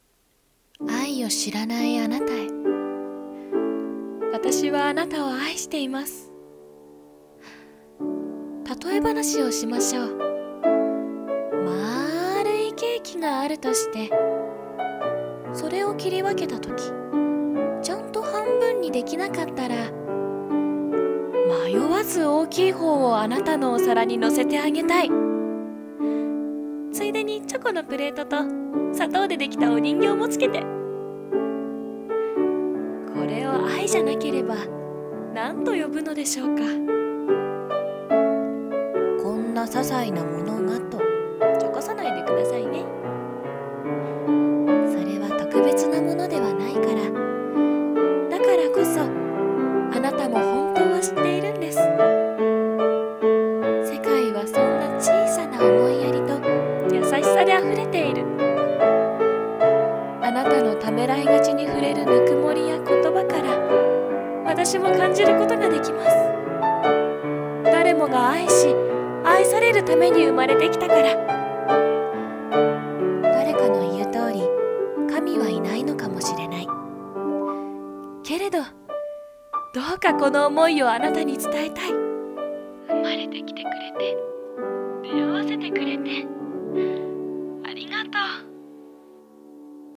一人声劇【愛を知らない貴方へ